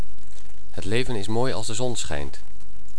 normaal
leven-norm.wav